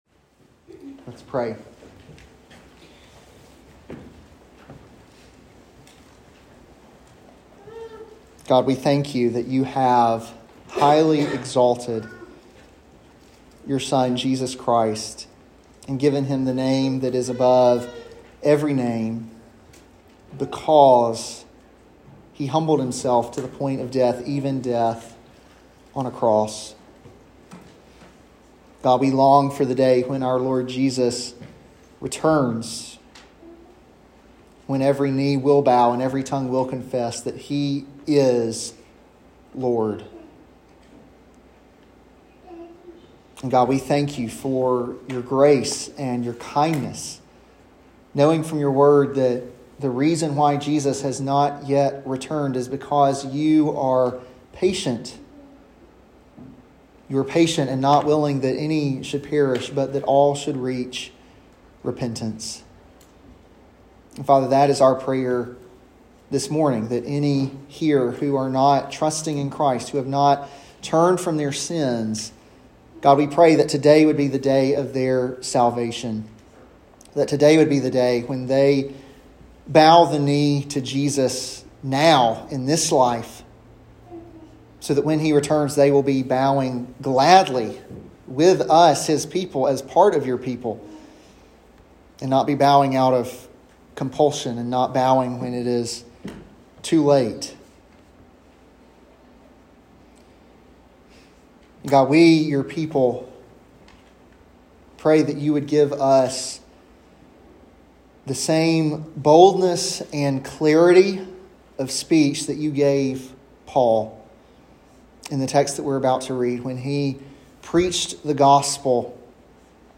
an expository sermon